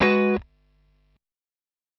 Abm7.wav